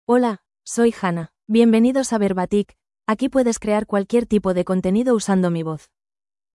Hannah — Female Spanish (Spain) AI Voice | TTS, Voice Cloning & Video | Verbatik AI
FemaleSpanish (Spain)
Hannah is a female AI voice for Spanish (Spain).
Voice sample
Listen to Hannah's female Spanish voice.
Hannah delivers clear pronunciation with authentic Spain Spanish intonation, making your content sound professionally produced.